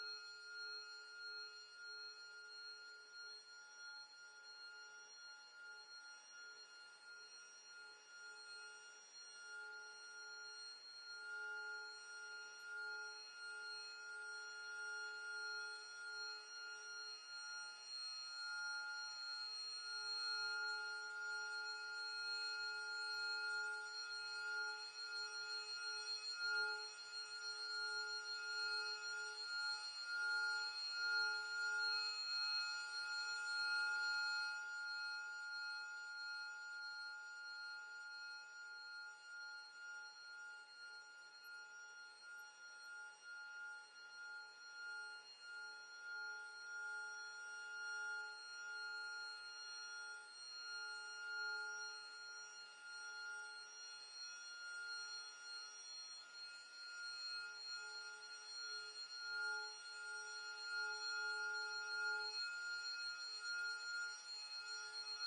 口琴无人机 " harm5
描述：用口琴创作的无人机。
Tag: 风能 声学环境 处理 无人驾驶飞机 样品 迷惑 口琴